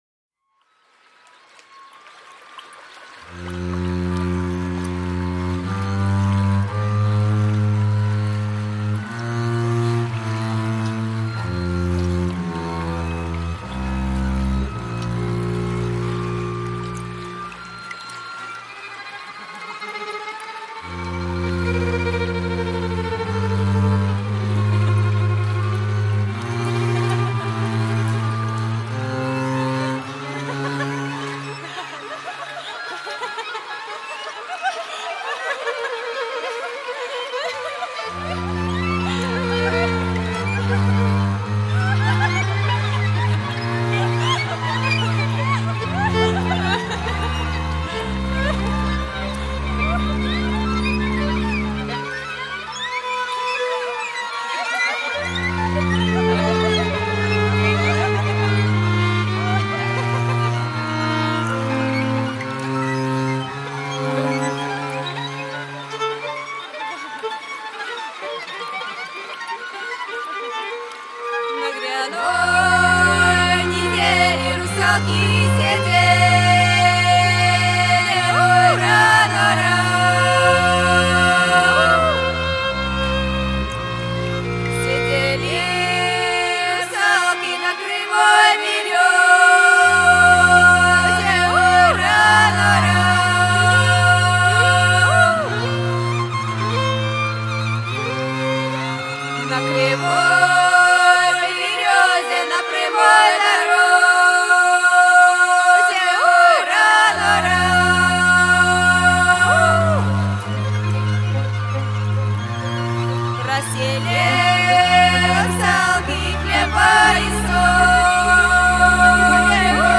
в жанре этно-минимализма
Альбом состоит из записанных в студии композиций
переработанные дуэтом народные песни